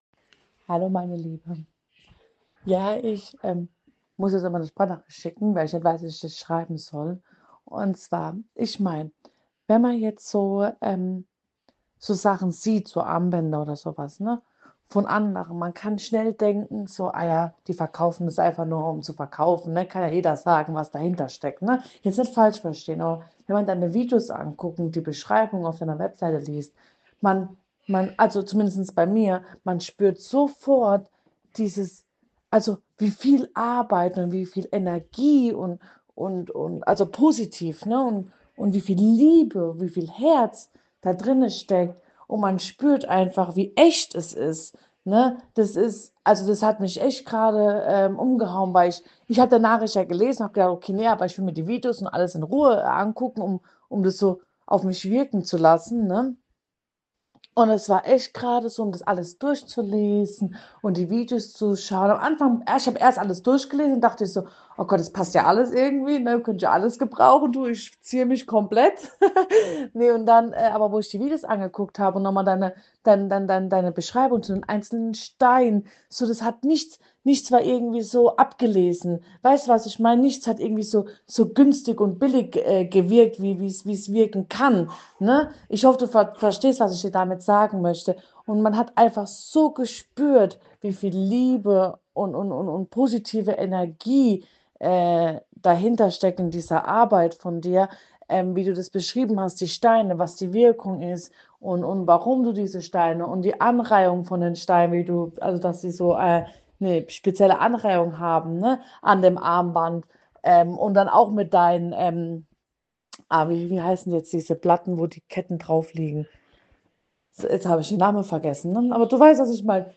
Eine wundervolle Kundenstimme zu meinen Diffuser-Edelstein-Armbändern